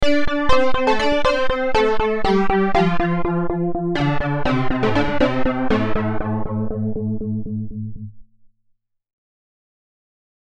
The two analogue settings are good all-rounders for basslines, leads and pads. Table excels at evolving pads and turns its hand well to slightly more aggressive leads. FM is great for bass, bell sounds and colder pads.